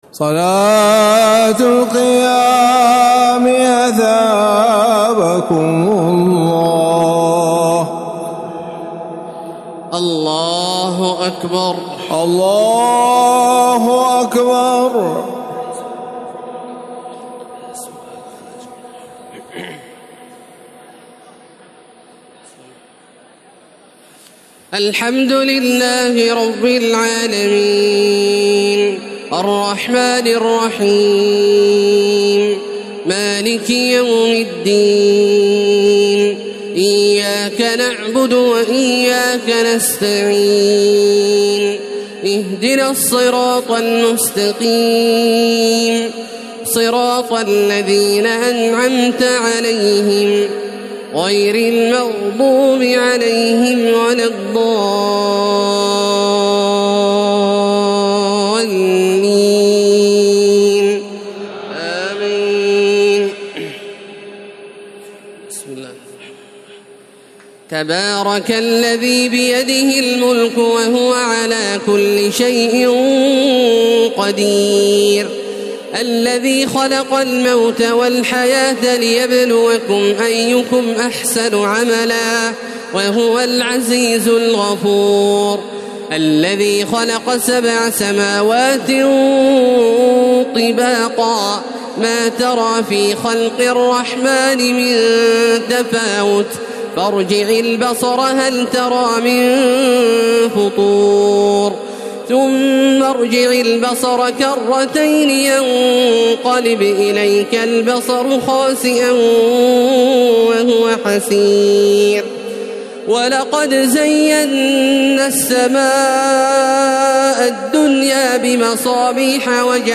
تراويح ليلة 28 رمضان 1432هـ من سورة الملك الى نوح Taraweeh 28 st night Ramadan 1432H from Surah Al-Mulk to Nooh > تراويح الحرم المكي عام 1432 🕋 > التراويح - تلاوات الحرمين